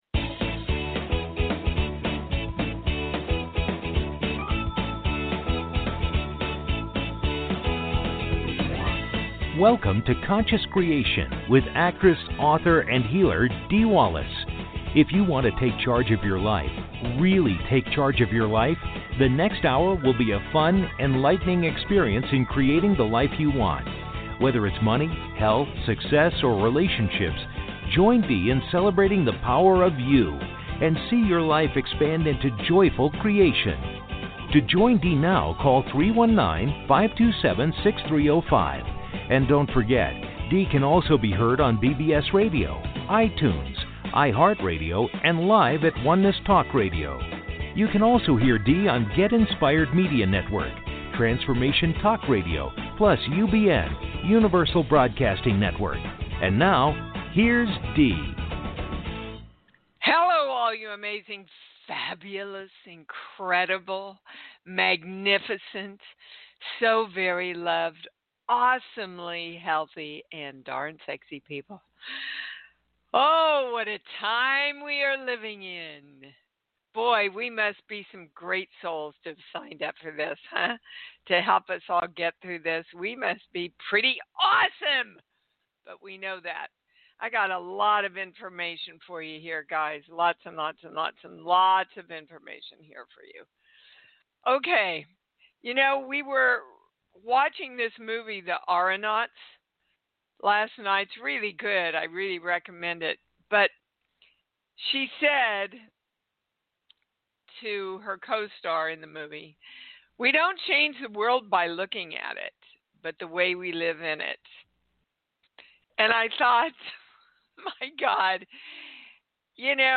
Talk Show Episode, Audio Podcast, Conscious Creation and with Dee Wallace on , show guests , about conscious creation,Dee Wallace,I am Dee Wallace, categorized as Kids & Family,Paranormal,Philosophy,Society and Culture,Spiritual,Access Consciousness,Medium & Channeling,Psychic & Intuitive,TV & Film